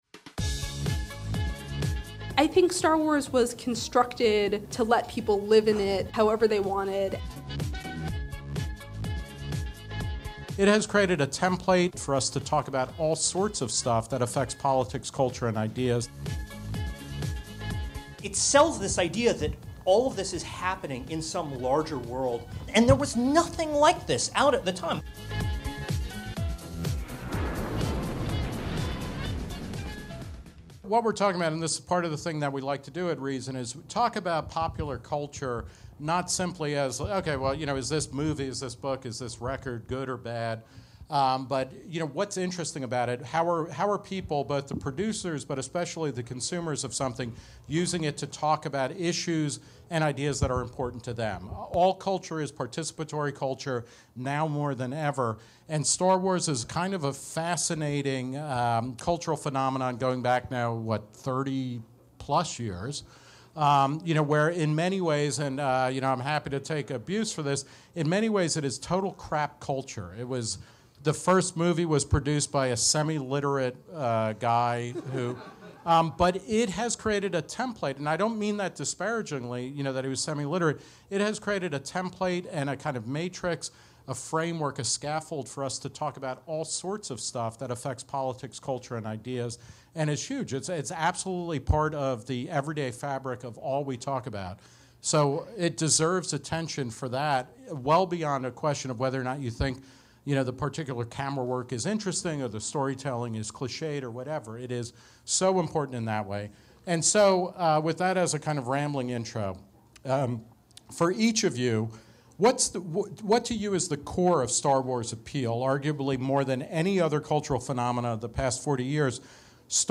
This is an edited version of an event that was held at Reason's D.C. HQ on December 3.